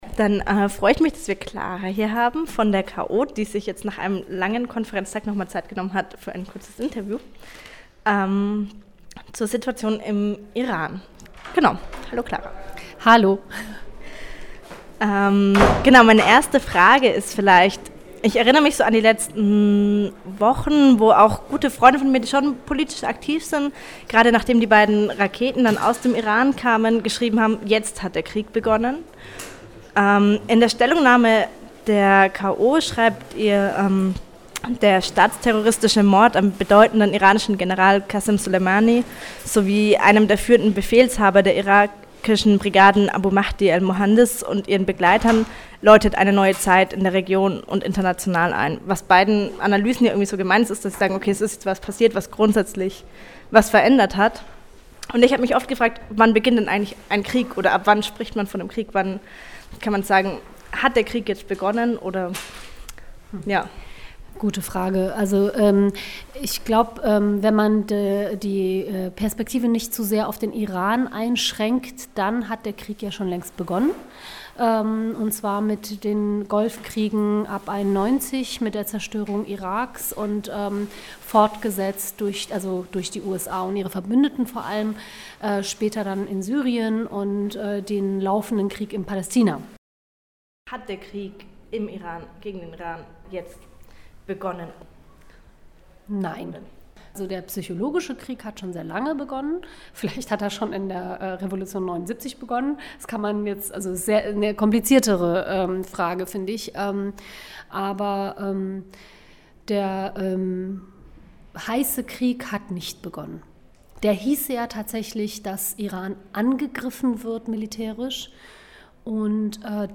Im vergangenen Jahr war die Situation im Iran immer wieder in den Schlagzeilen. Wir haben Ausschnitte aus zwei Vorträgen, die im vergangenen Jahr in Tübingen zum Iran gehalten wurden, für Euch zusammengestellt. Außerdem spricht eine Vertreterin der Kommunistischen Organisation über deren aktuelle Stellungnahme zur Lage in Westasien.
Zudem haben wir eine Vertreterin der Kommunistische Organisation (KO) zu ihrer aktuell veröffentlichten Stellungnahme zur Lage in Westasien interviewt.
73179_Interview_KO_geschnitten.mp3